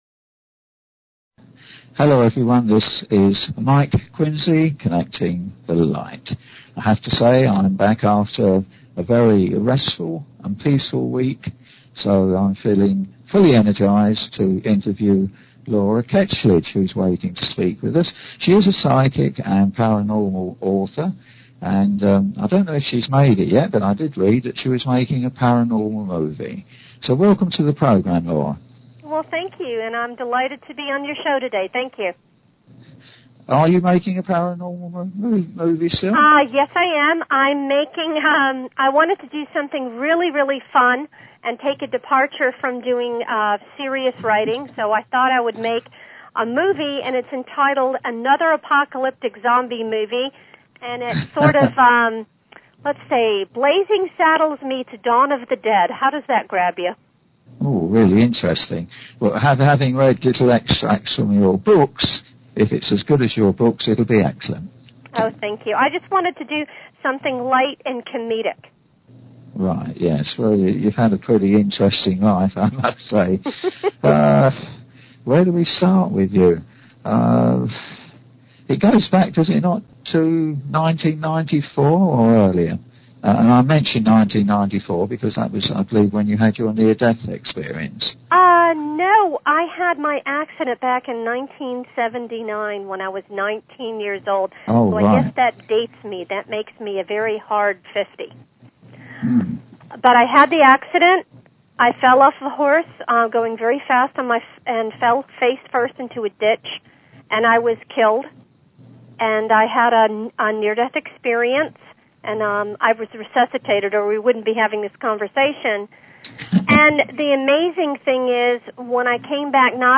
Talk Show Episode, Audio Podcast, Connecting_The_Light and Courtesy of BBS Radio on , show guests , about , categorized as